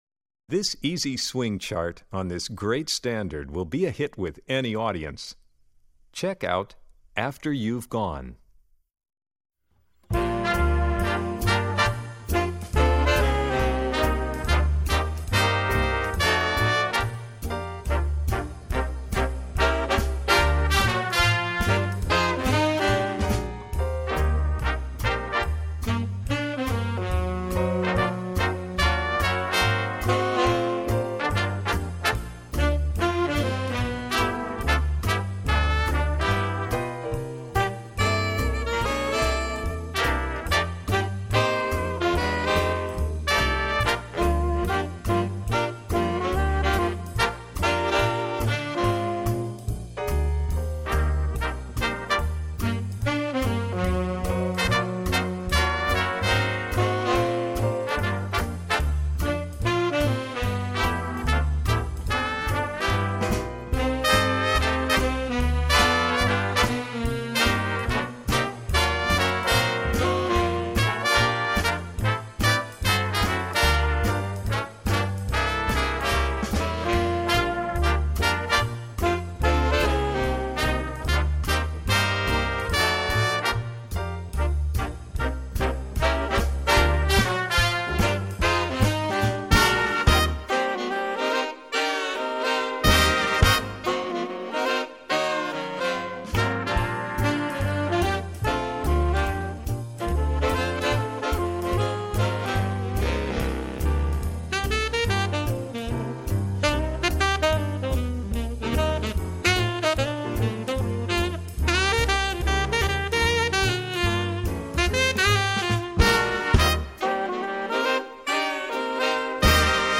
Voicing: Alto Saxophone 2